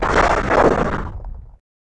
Index of /App/sound/monster/chaos_ghost